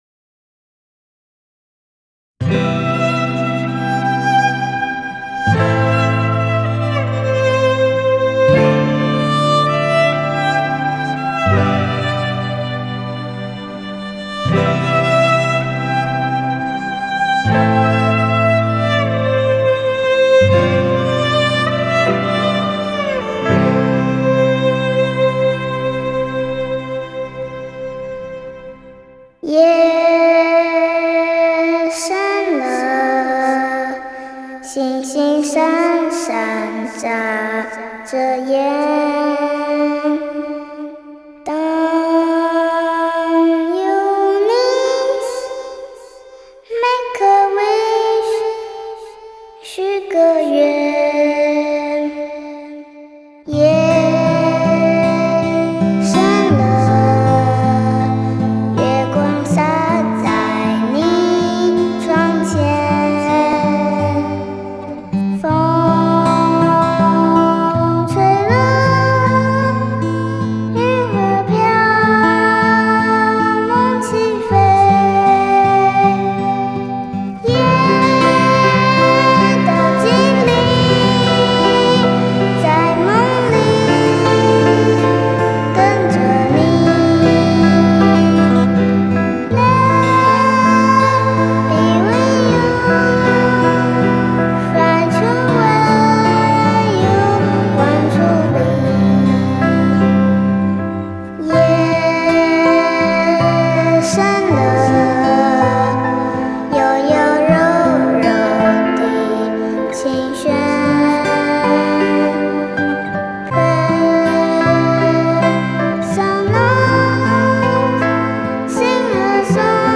[9/10/2007]最简单的旋律，最纯真的童声，却最深入人心，直达心灵。
充满童稚的小可爱的声音。